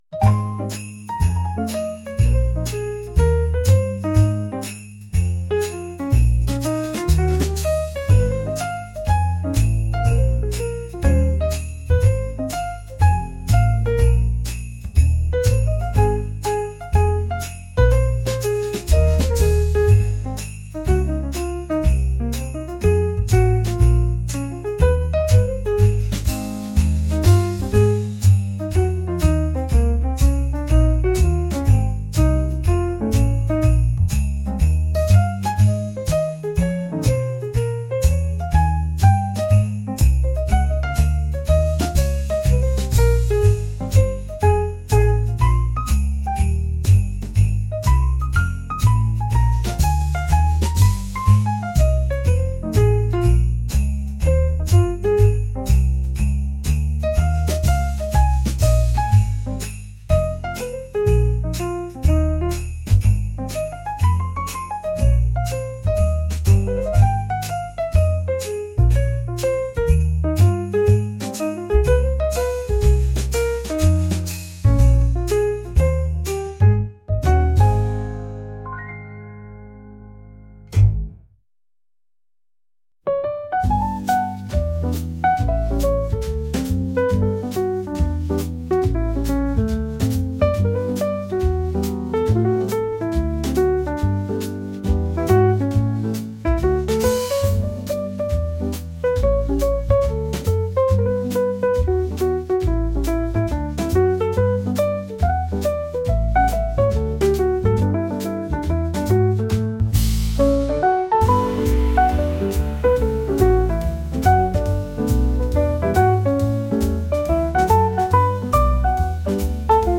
Lo-Fi Jazz brings relaxation and focus.